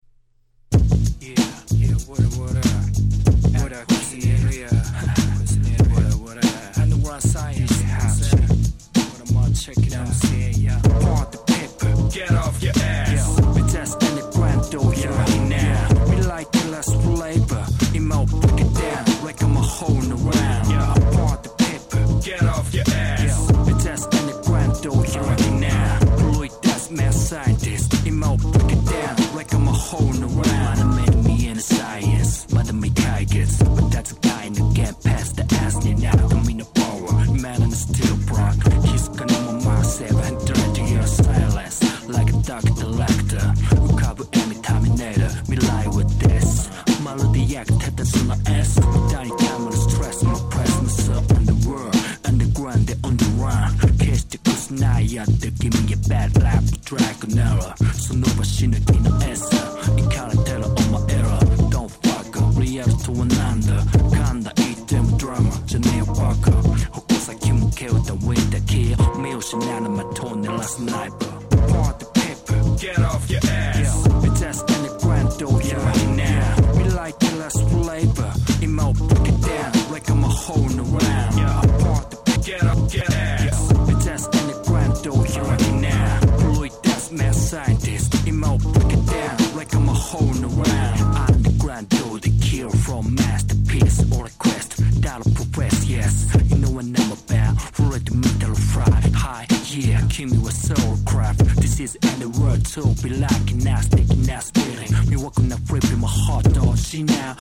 11' Dope Hip Hop !!!!!!!!!